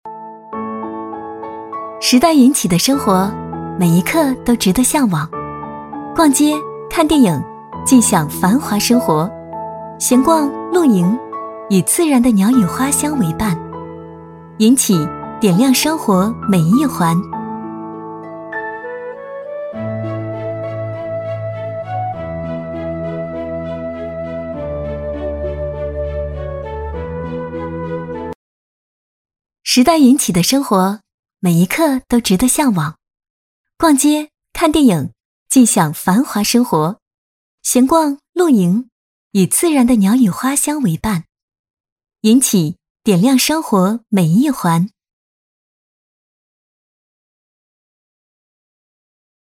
• 房地产广告配音
女B25-地产宣传片【时代云起 轻松舒适】
女B25-地产宣传片【时代云起 轻松舒适】.mp3